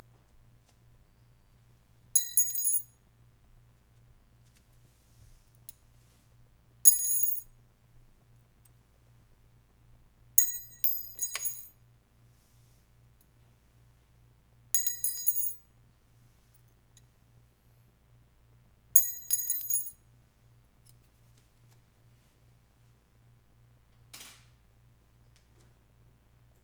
bullet bullet-casings casing ding ejected empty foley shell sound effect free sound royalty free Voices